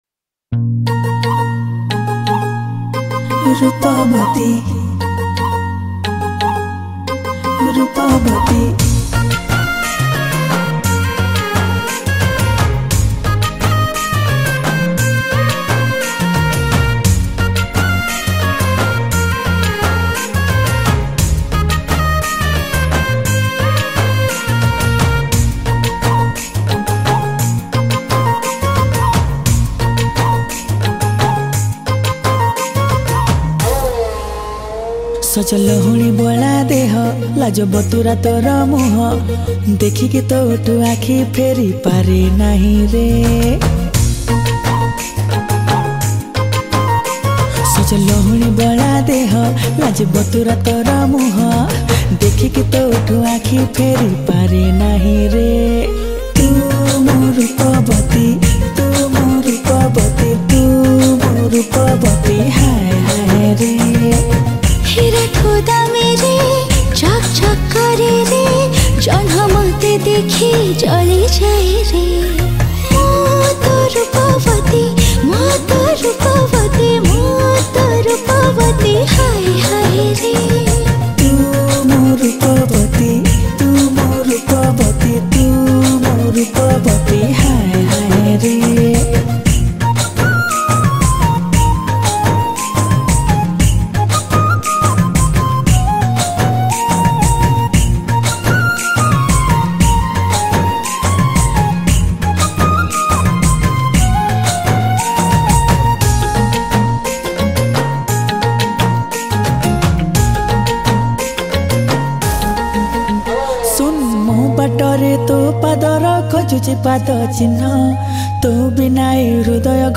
Official Studio Version Teaser | Romantic Dance Odia Song